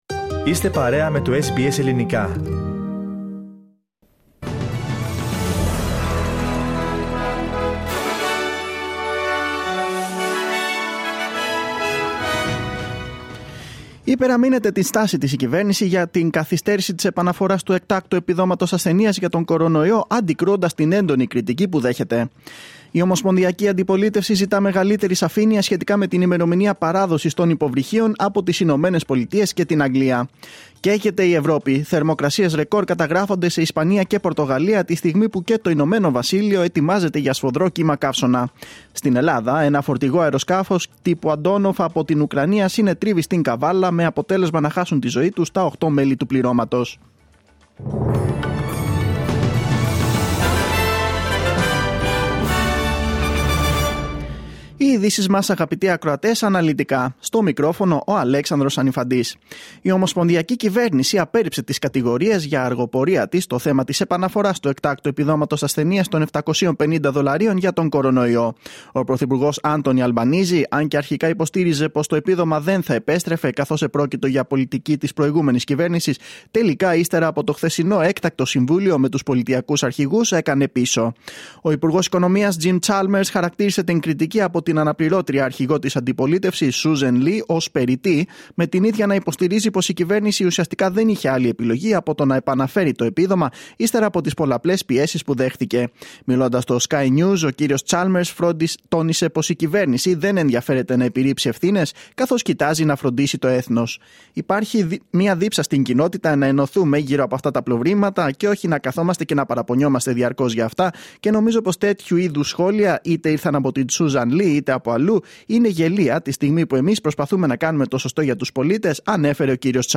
Δελτίο Ειδήσεων Κυριακή 17.7.2022